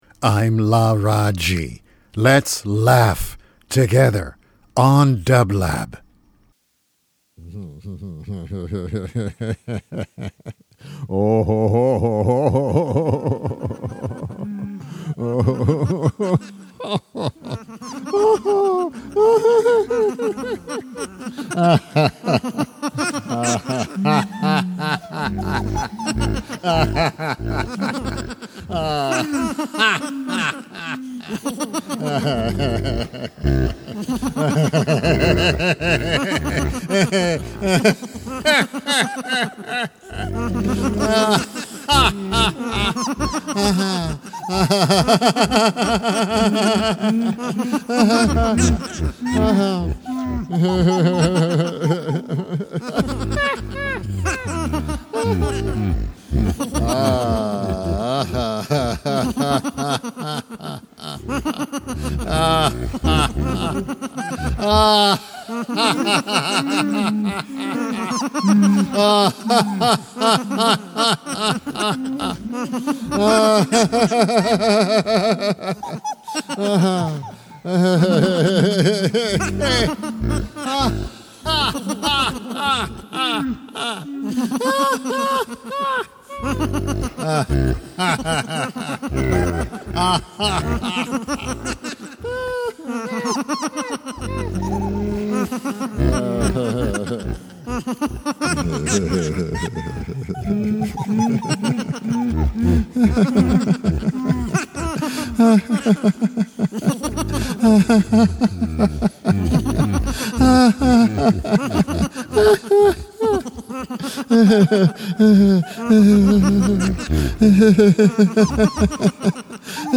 Every Thursday, Laraaji energizes the dublab airwaves with three minutes of luminous laughter flowing on celestial music beds.